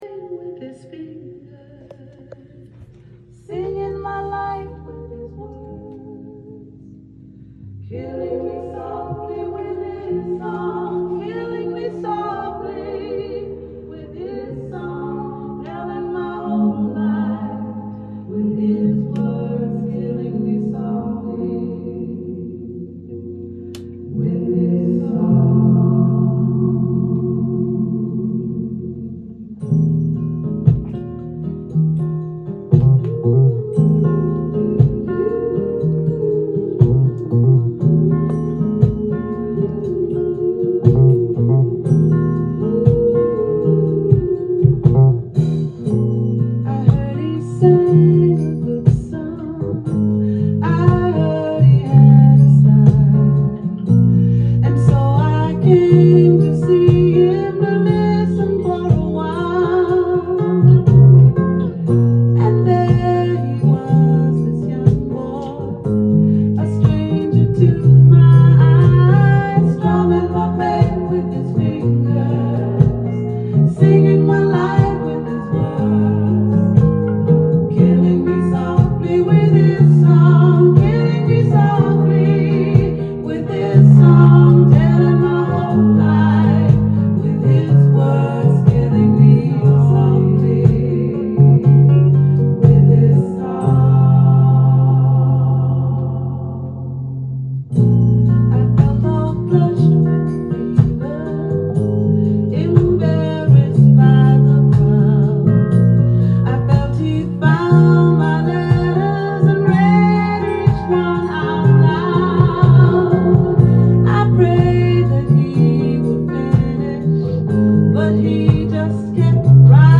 ジャンル：SOUL
店頭で録音した音源の為、多少の外部音や音質の悪さはございますが、サンプルとしてご視聴ください。
エレガントな彼女の魅力溢れる歌声が響くメロウな名盤！！